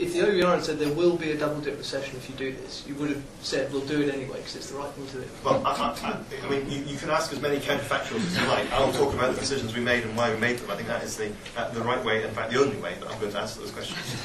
SMF growth fringe event: Danny Alexander on OBR forecasts